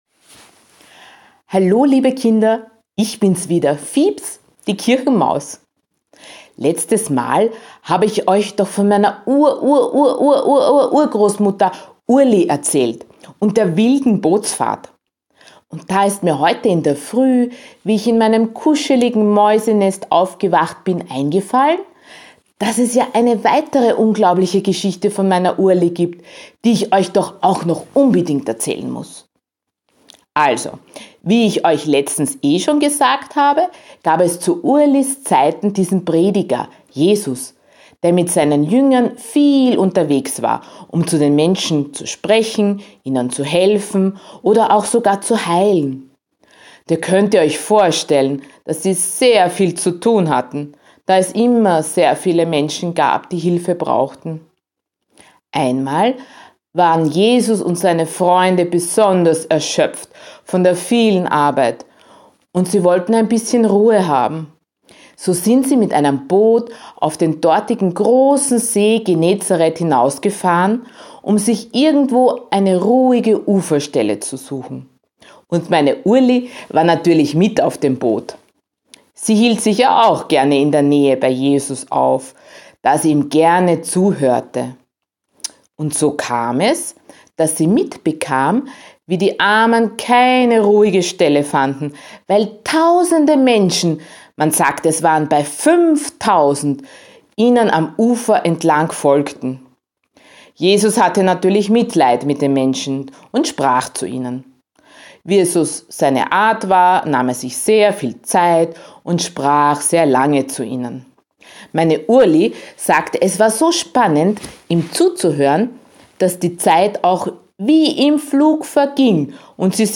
Geschichte: Die Brotvermehrung